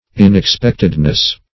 Inexpectedness \In`ex*pect"ed*ness\, n. Unexpectedness.